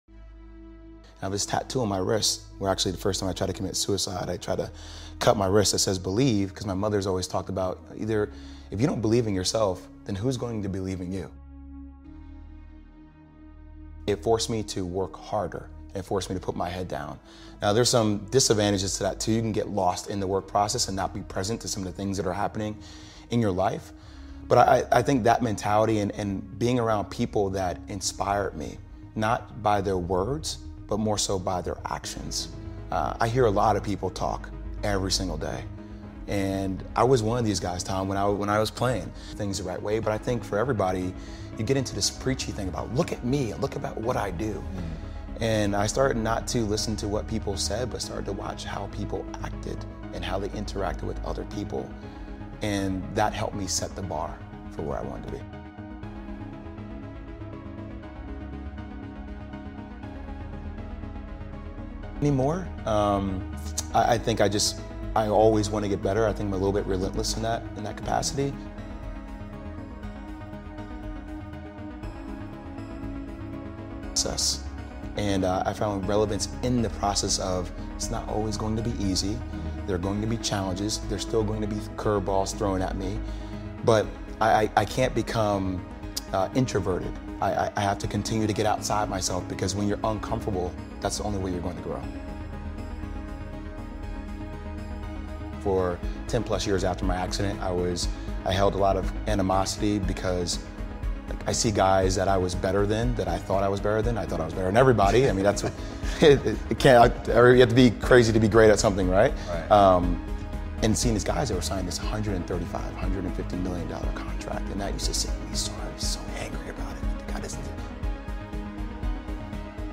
Incredible Speech on Drive: Lessons for Success Through Determination